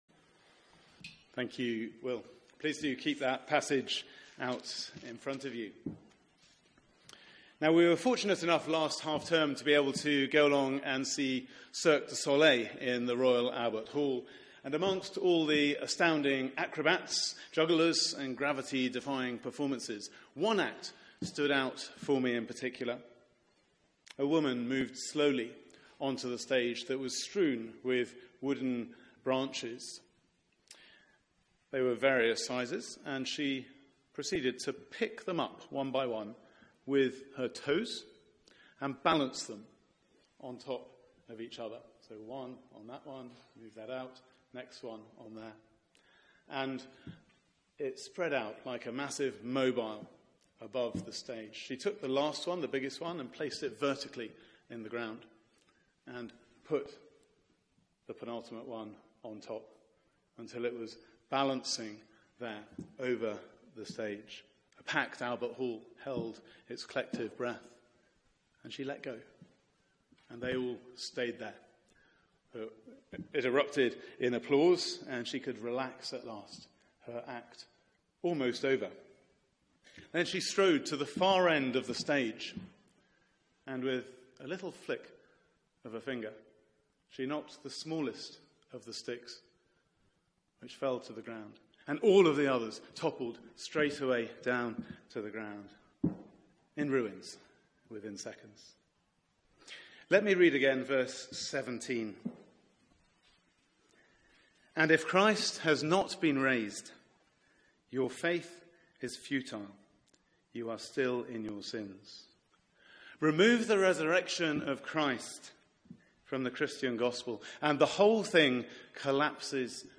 Media for 4pm Service on Sun 30th Apr 2017 16:00 Speaker